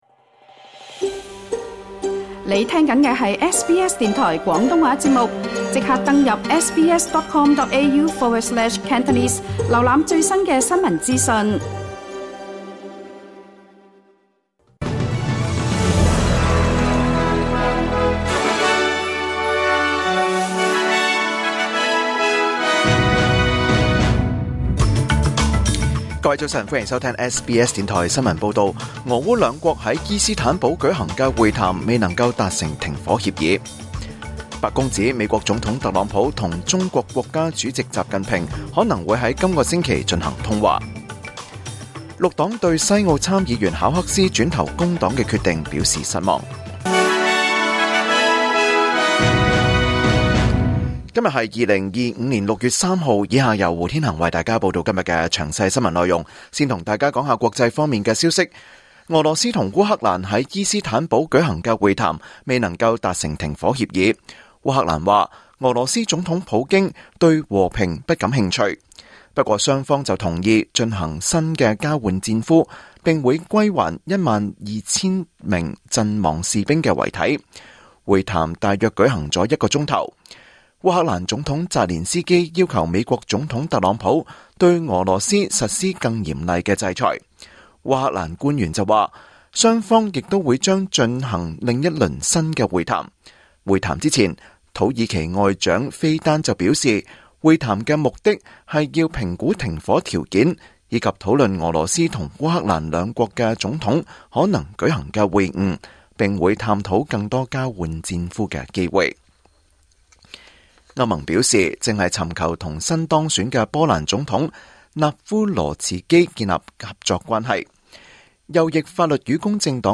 2025年6月3日SBS廣東話節目九點半新聞報道。